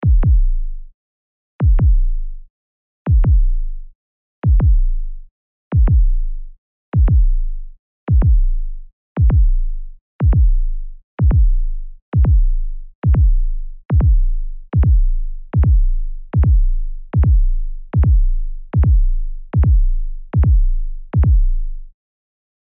دانلود آهنگ قلب انسانی که ترسیده از افکت صوتی انسان و موجودات زنده
دانلود صدای قلب انسانی که ترسیده از ساعد نیوز با لینک مستقیم و کیفیت بالا
جلوه های صوتی